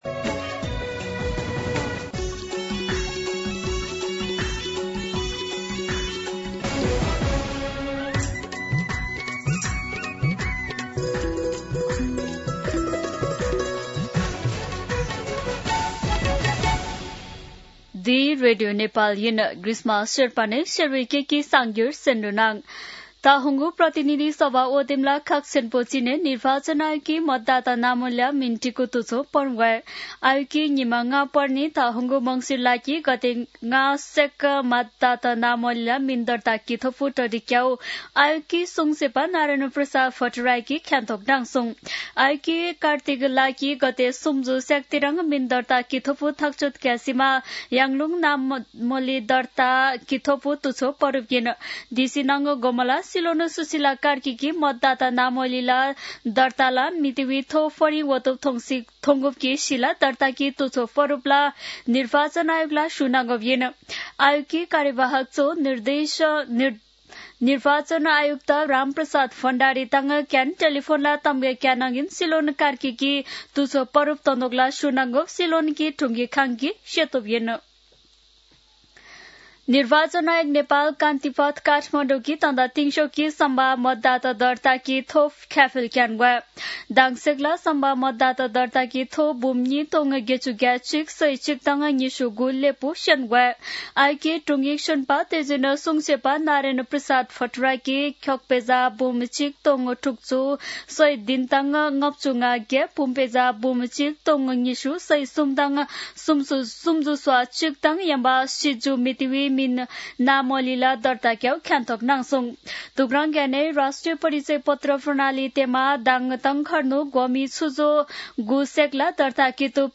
शेर्पा भाषाको समाचार : २९ कार्तिक , २०८२
Sherpa-News-07-29-.mp3